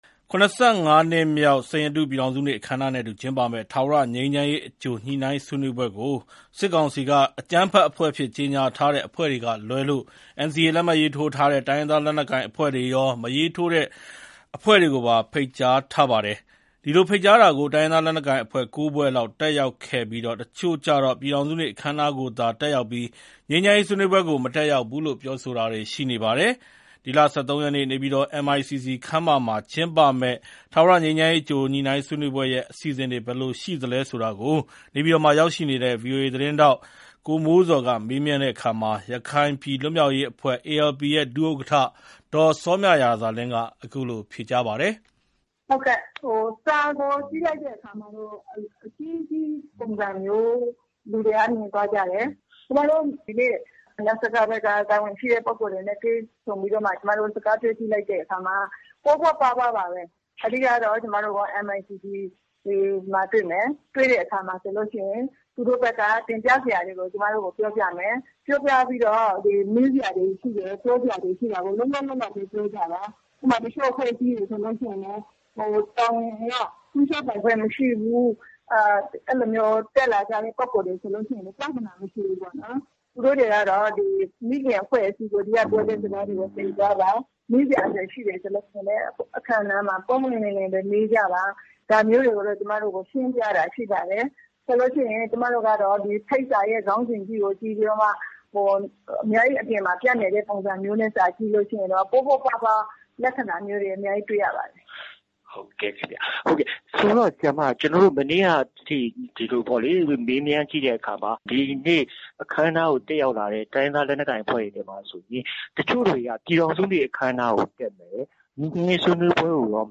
စစ်ကောင်စီရဲ့ ငြိမ်းချမ်းရေး အကြိုဆွေးနွေးပွဲ အလားအလာ မေးမြန်းချက်